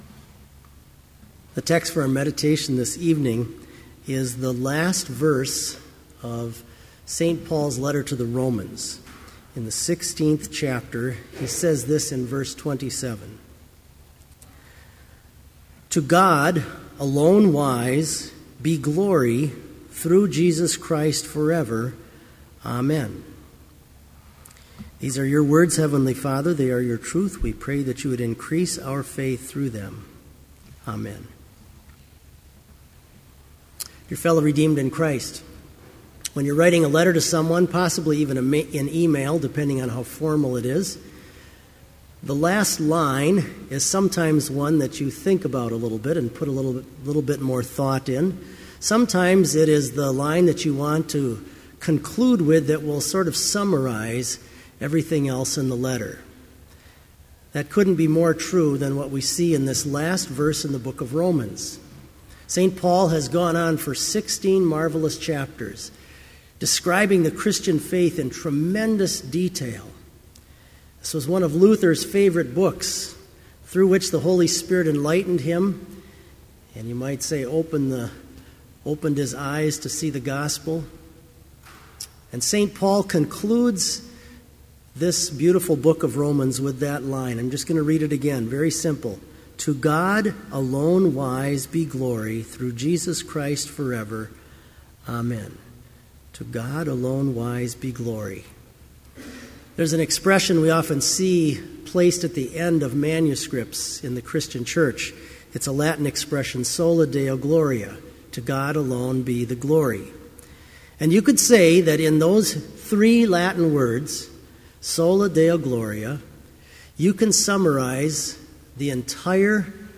Sermon Only
This Vespers Service was held in Trinity Chapel at Bethany Lutheran College on Wednesday, October 31, 2012, at 5:30 p.m. Page and hymn numbers are from the Evangelical Lutheran Hymnary.